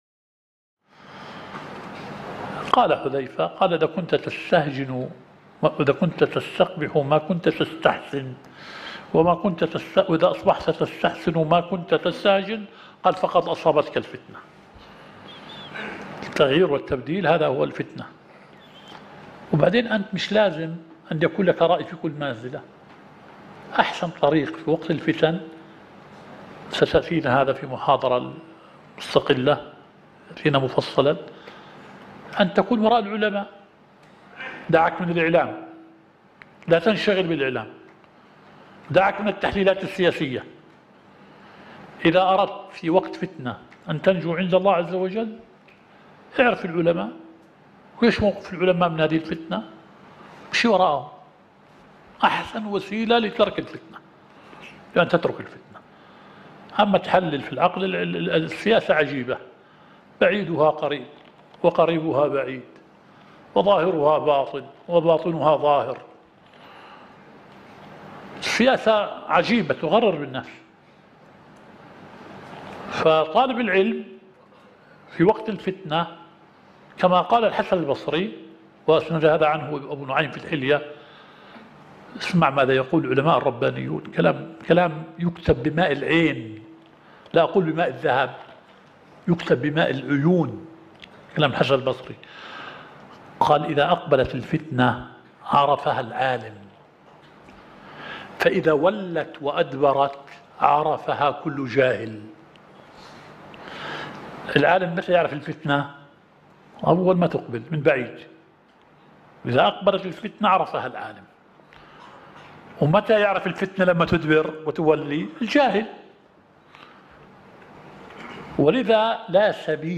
الدورة الشرعية الثالثة للدعاة في اندونيسيا – منهج السلف في التعامل مع الفتن – المحاضرة الثالثة.